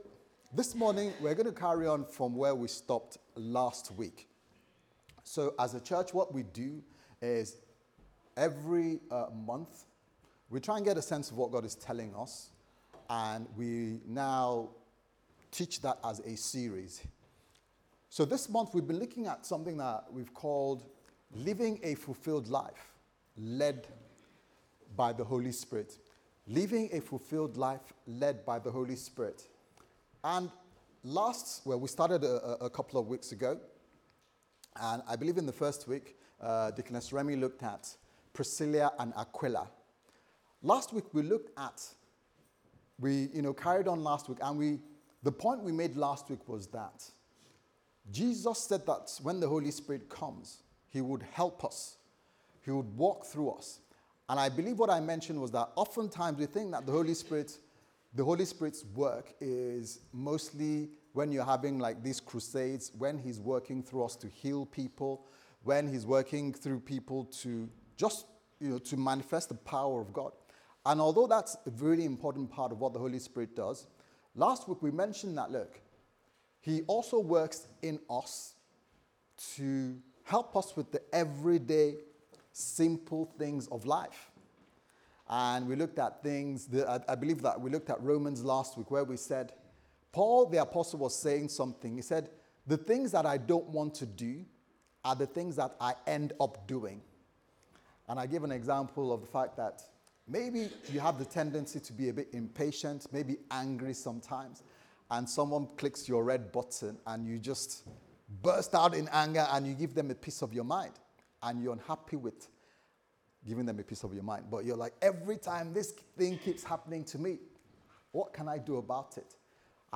Service Type: Sunday Service Sermon